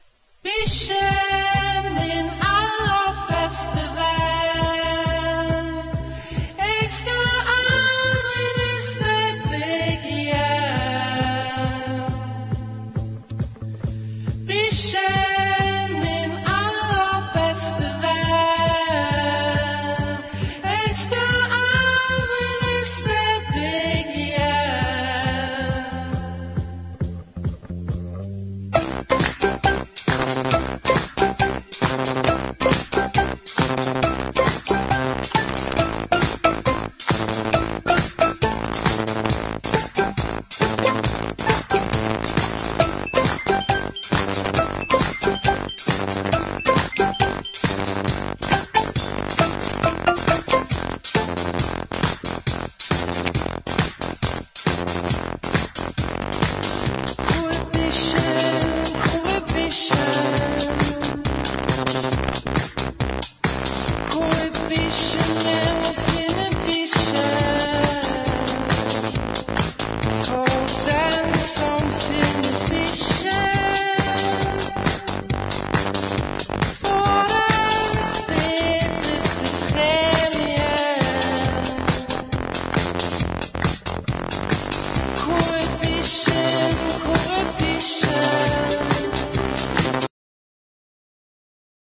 今回もトロピカルなナンバーです。
# INDIE DANCE